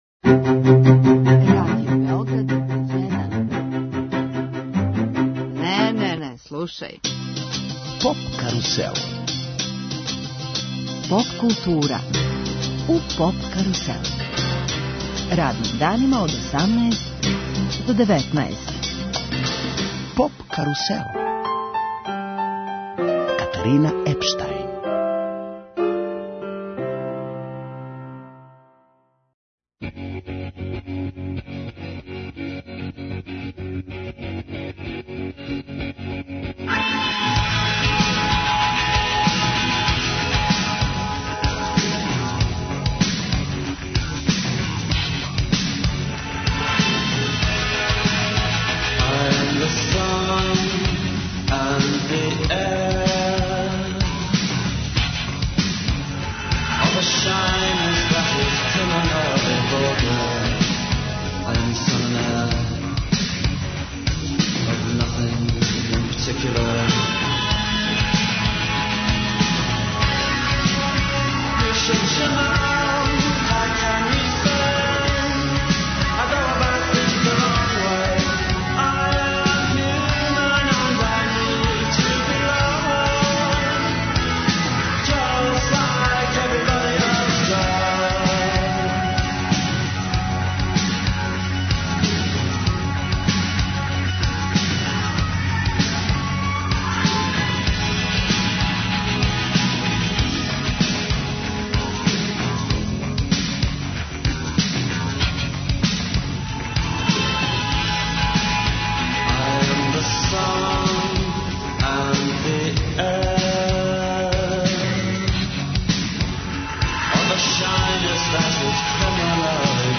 Гости емисије су чланови састава Земља грува и Sharks, Snakes & Planes, поводом концертних активности.